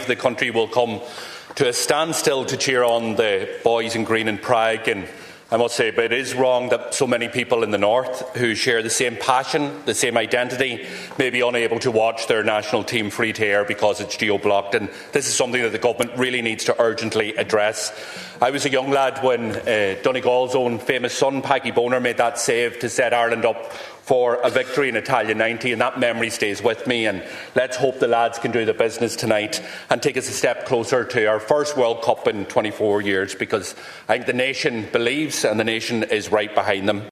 Donegal TD Pearse Doherty has told the Dail it’s wrong that tonight’s crucial world cup qualifier will not be shown free to air north of the border.